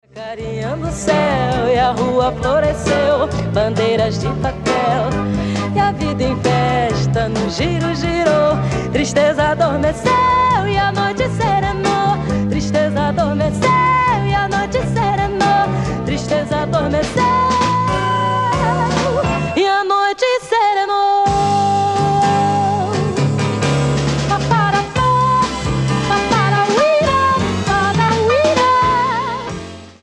recorded in London during her European stay.